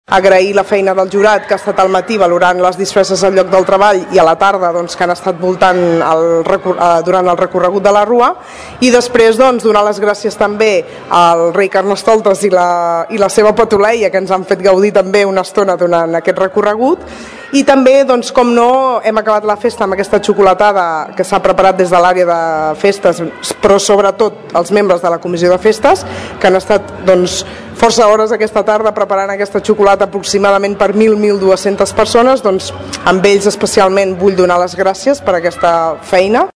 Català ha aprofitat els micròfons de Ràdio Tordera per agrair la feina al jurat del concurs de disfresses, al Rei Carnestoltes i la seva família i a la comissió de festes per la preparació de la xocolatada.